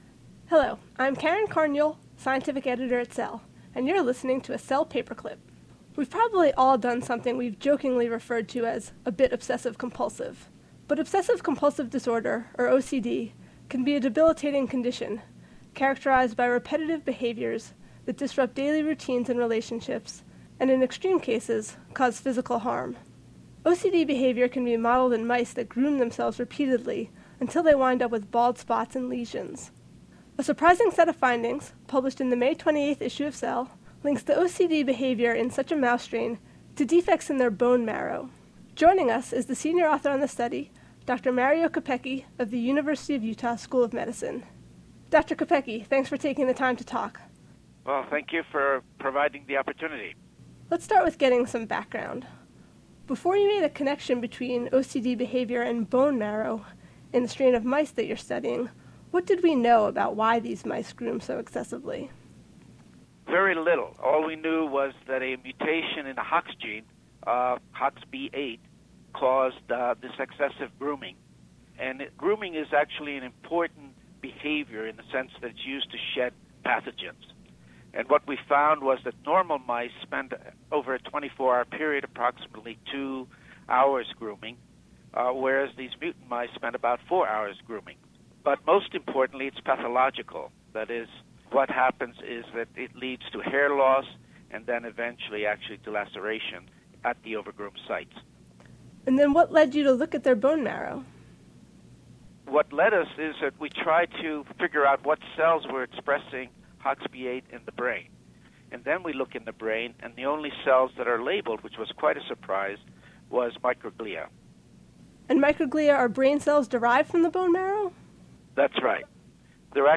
Interview with Prof. Dr. Mario Capecchi